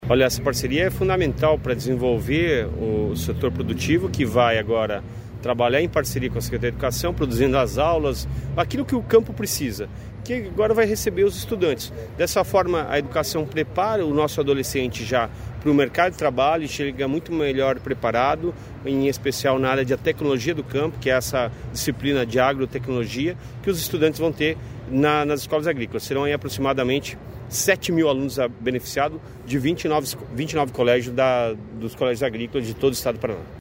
Sonora do secretário Estadual da Educação, Roni Miranda, sobre sobre a parceria com a New Holland para colégios agrícolas